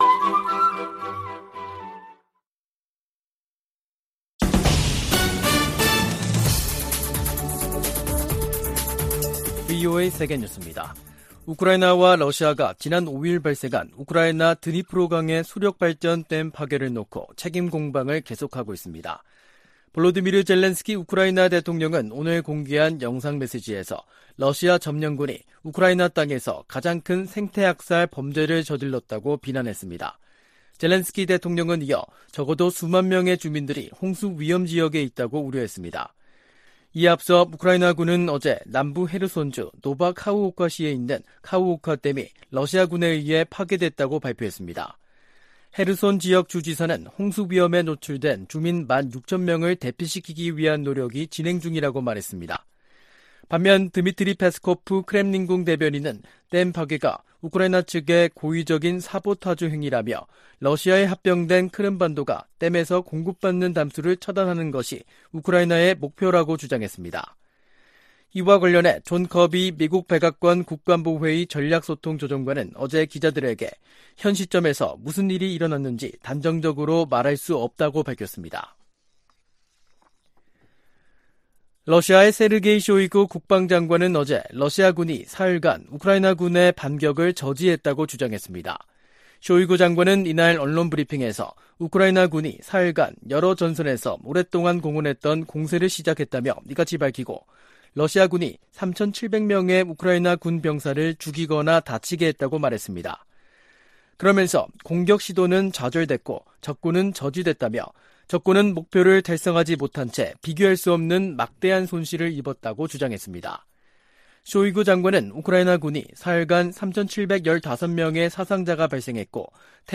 VOA 한국어 간판 뉴스 프로그램 '뉴스 투데이', 2023년 6월 7일 2부 방송입니다. 한국이 유엔 안전보장이사회 비상임이사국으로 다시 선출됐습니다.